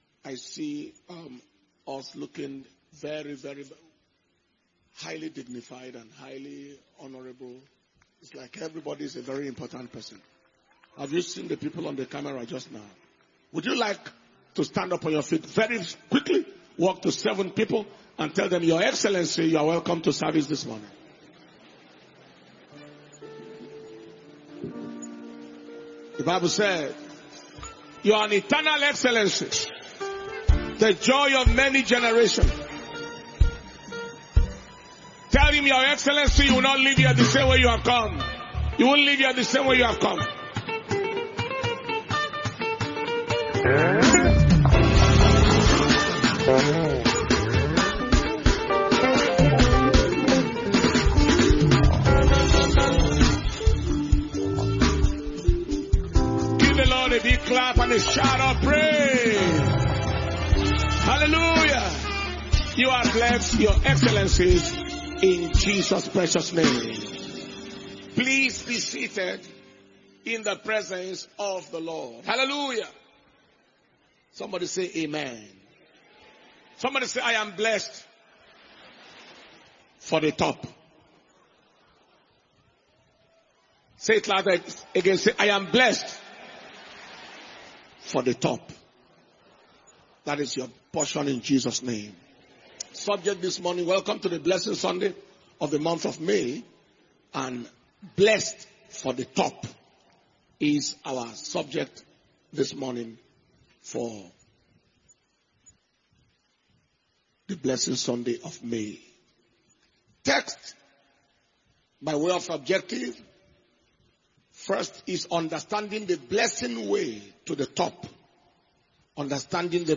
May 2024 Blessing Sunday Service